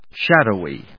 /ʃˈædoʊi(米国英語), ˈʃædəʊi:(英国英語)/